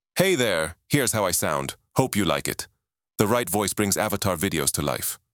🌍 Multilingual👨 Мужской
Пол: male